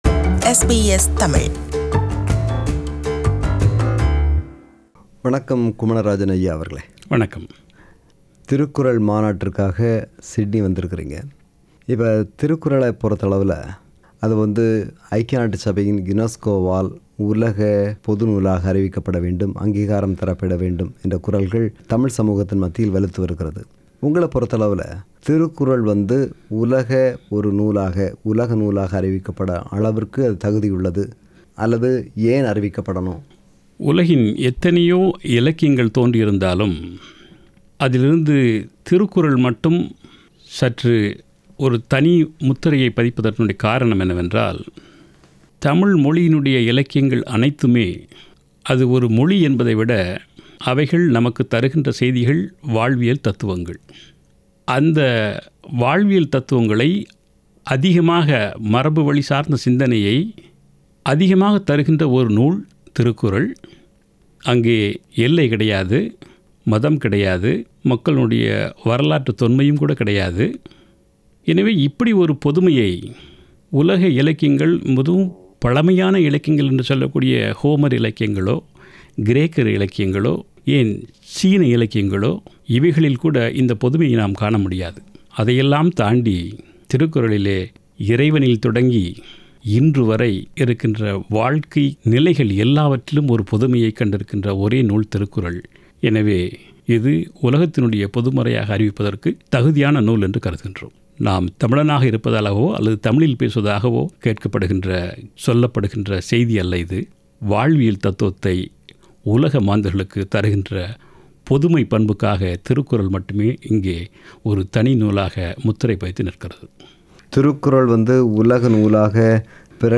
நேர்முகம் பாகம் 1.